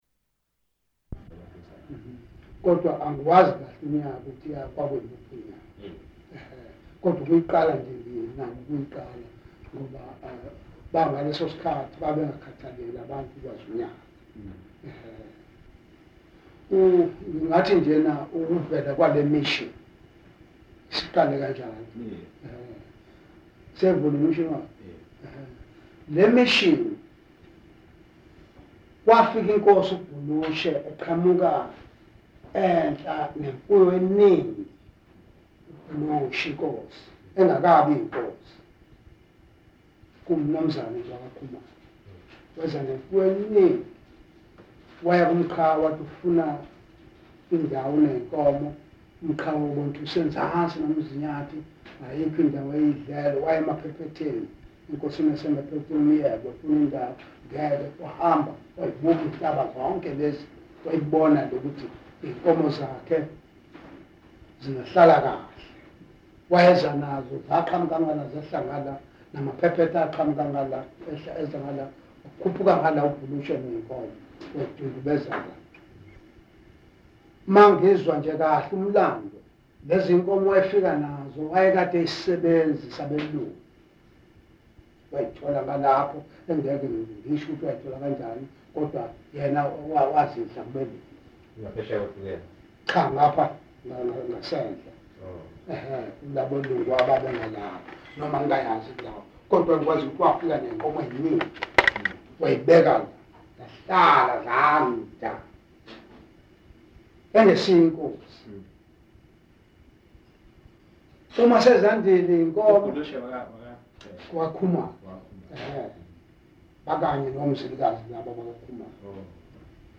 Interview-recording